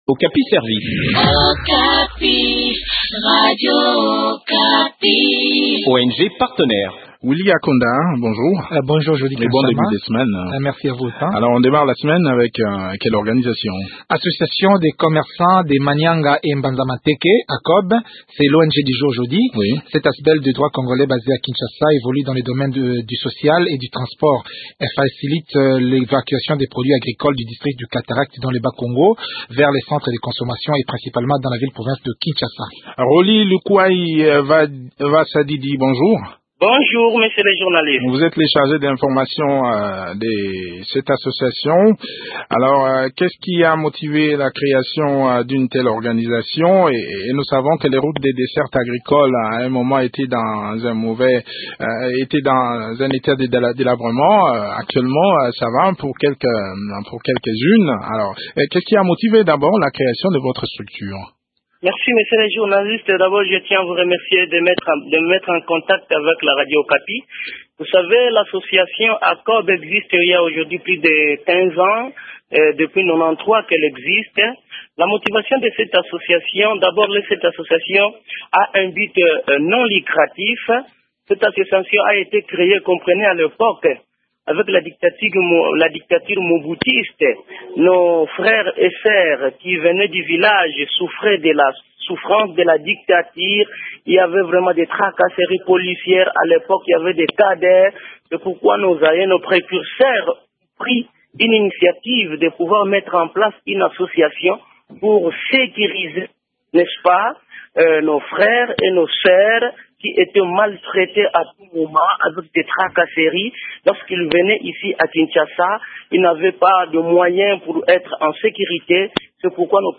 fait le point de leurs activités au micro de